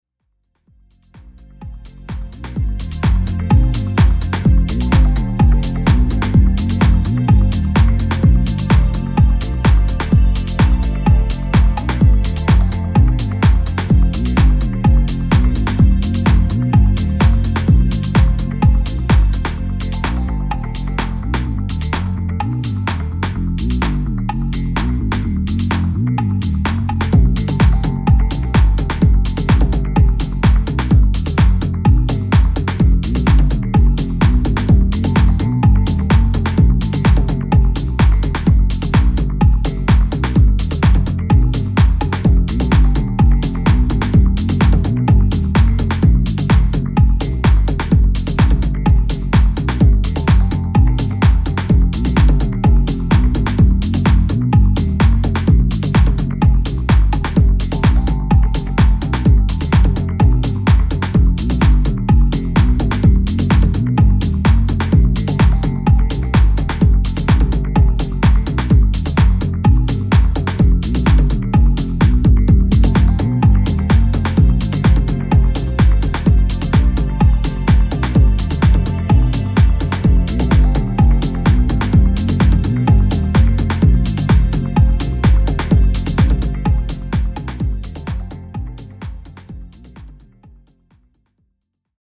groovy